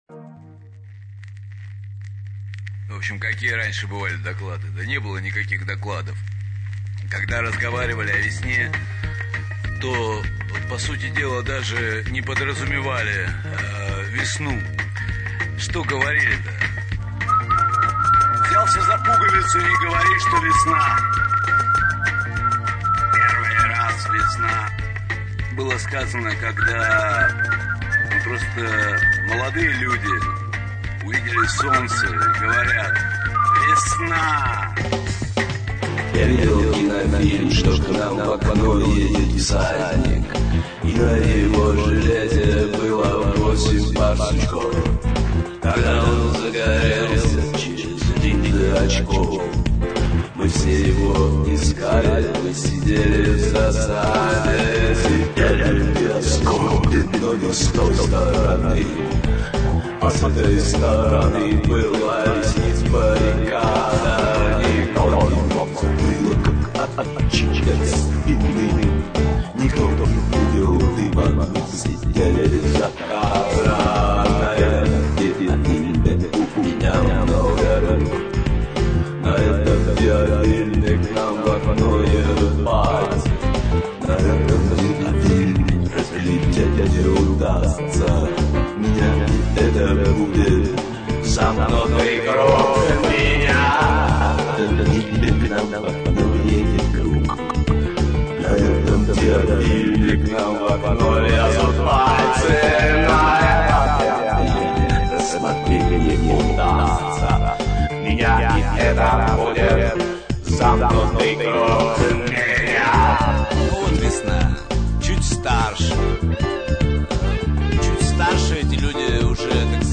вокал, свист, гитара
ритм-бокс, электронный бас и фортепьяно
Всю композицию (mono, 40 kbps, 886 kb) вы можете скачать